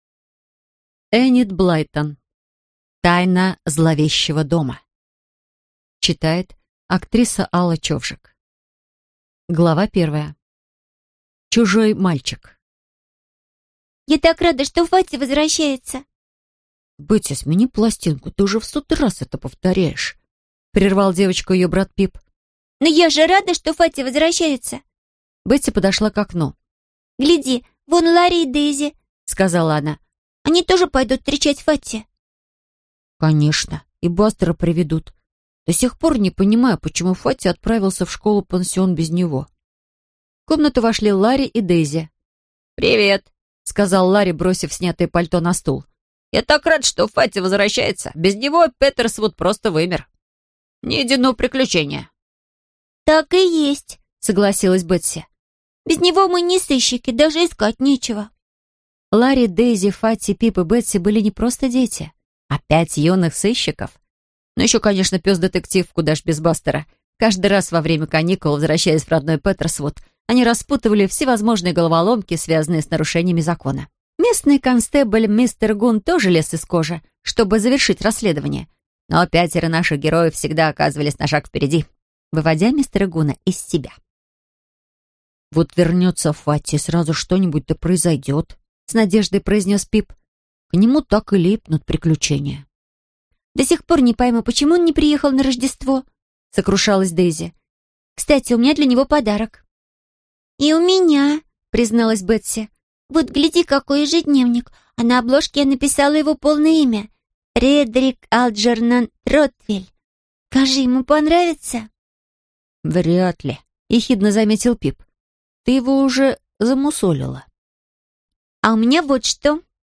Аудиокнига Тайна зловещего дома | Библиотека аудиокниг